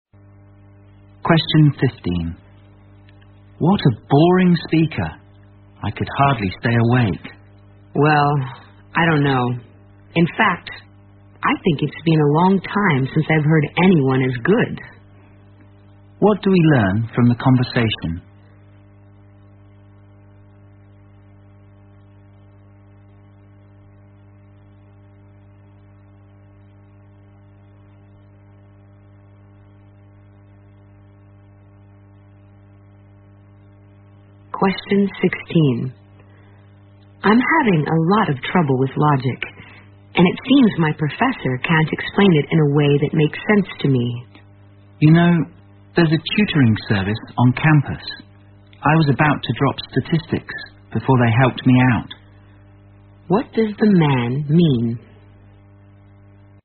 在线英语听力室026的听力文件下载,英语四级听力-短对话-在线英语听力室